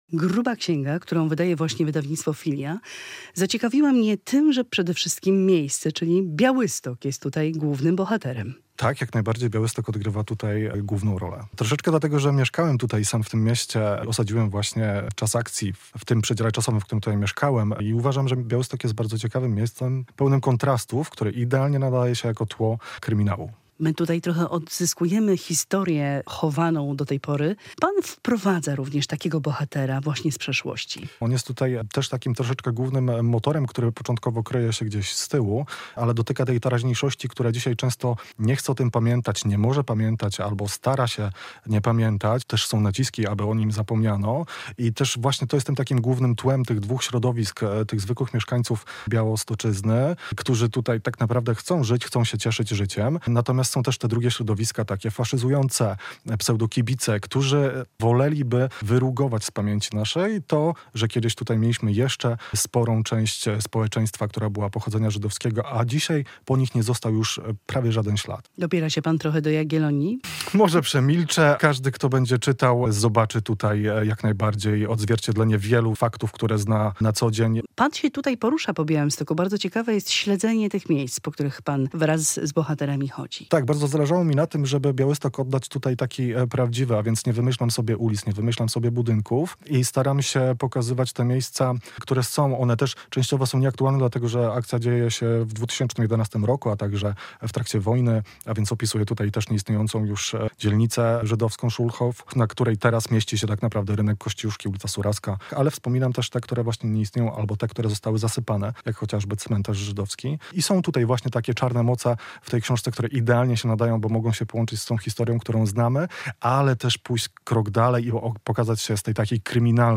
Prowadzący: